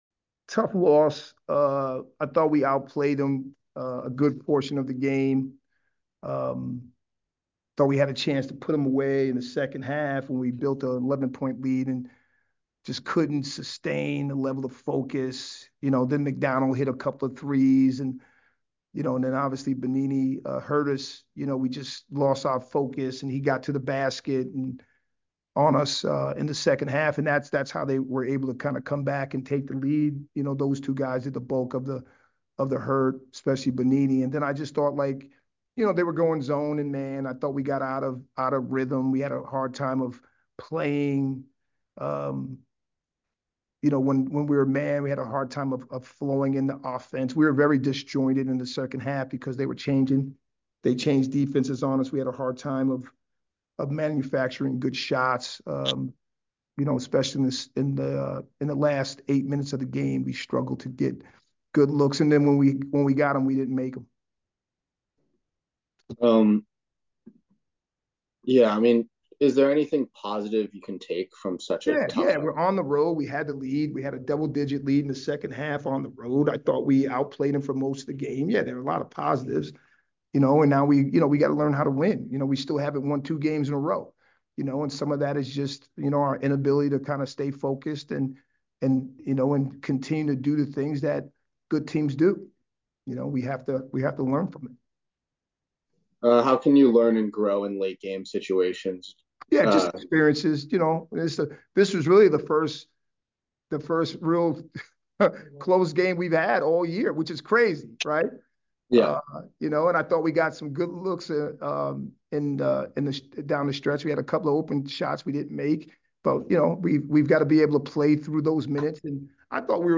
Navy Postgame Interview